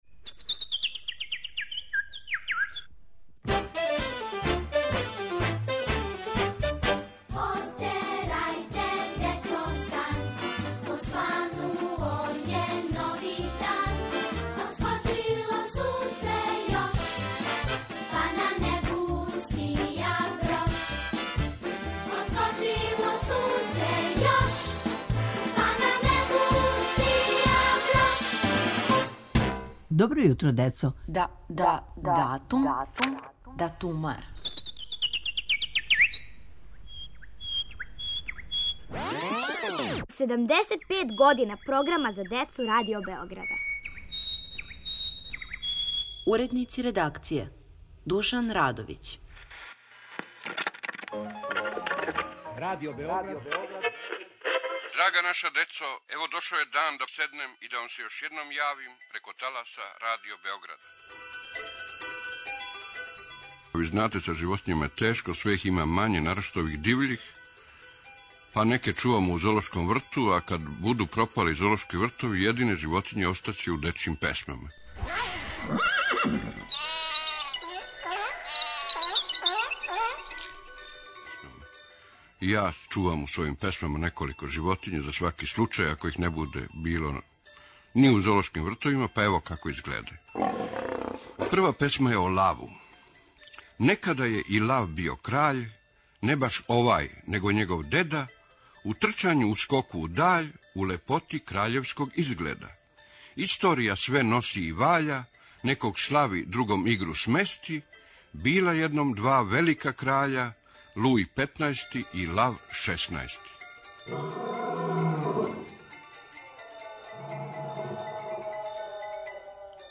Недељом прослављамо 75. рођендан наше редакције причама о досадашњим уредницима. Данас - Душан Радовић казује своје песме о животињама.